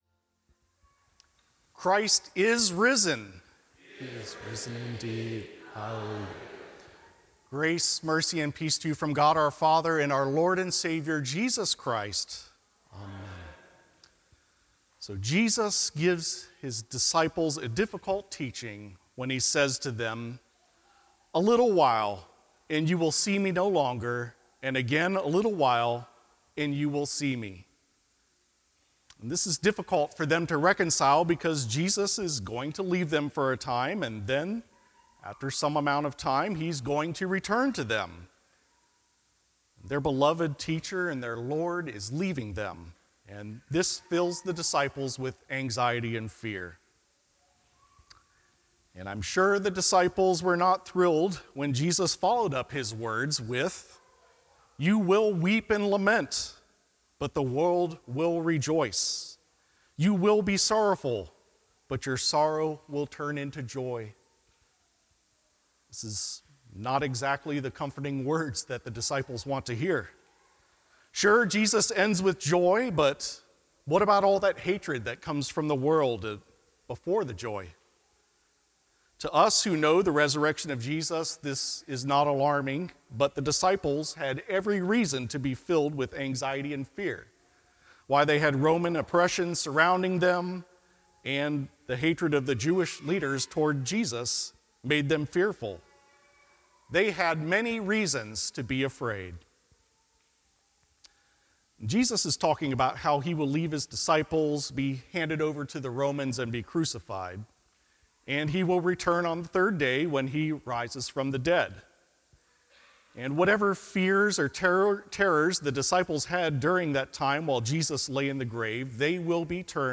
Jubilate – Fourth Sunday of Easter